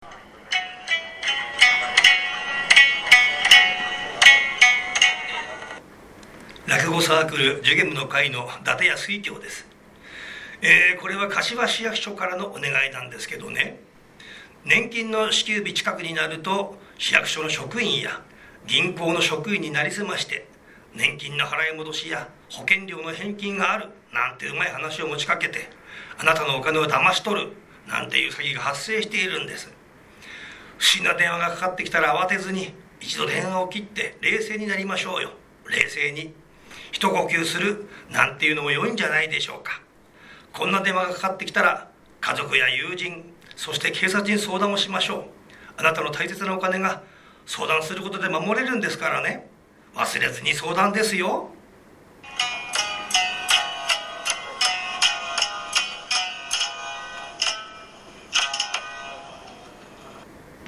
4月15日（水曜日）より、もっと身近に防犯を意識してもらえるような、思わず耳に残る、やさしくて分かりやすい音声を使用します。
アマチュア落語家